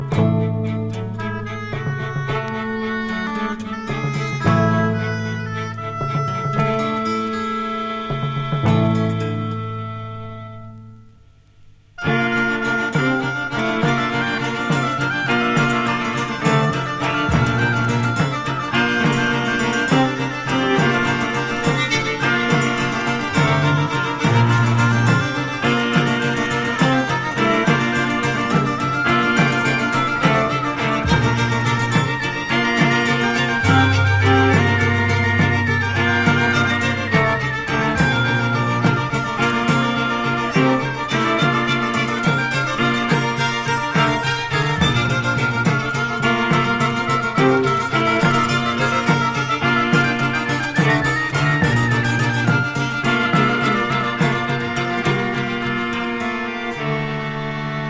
イタリアン前衛トラッド集団72年作。
中世トラッド不穏エクスペリメンタル・サントラ